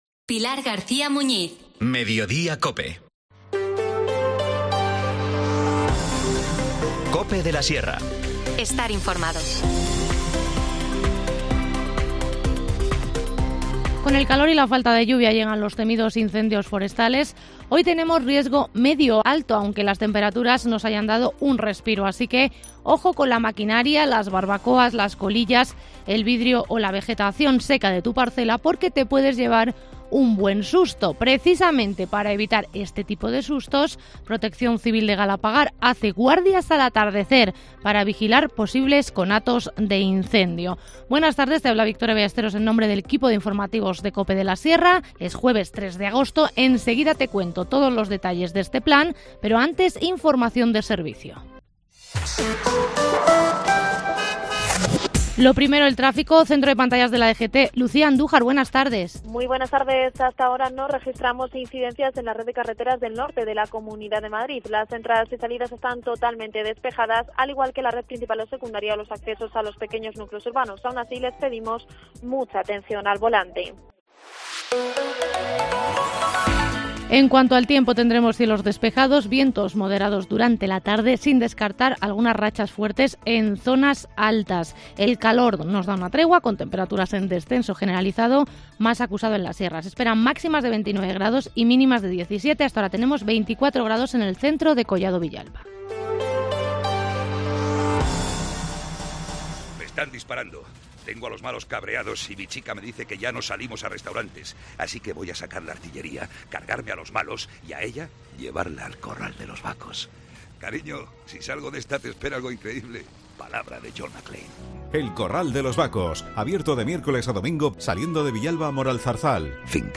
Informativo Mediodía 3 agosto